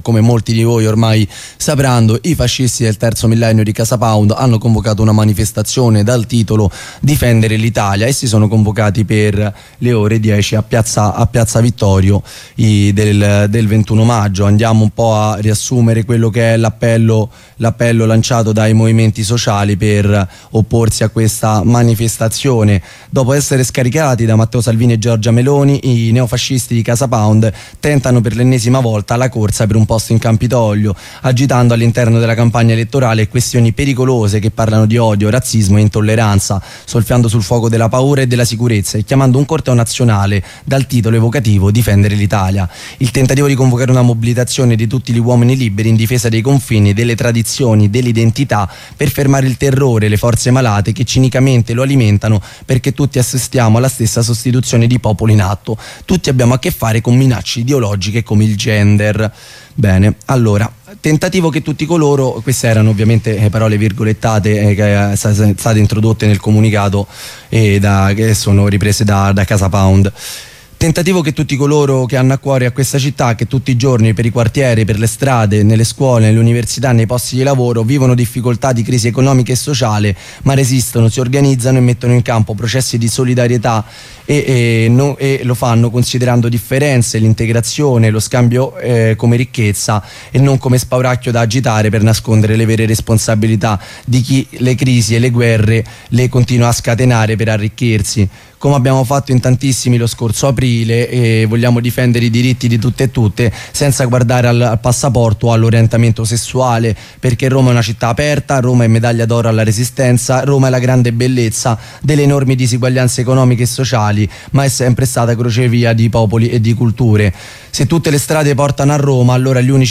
Assemblea antifascista.ogg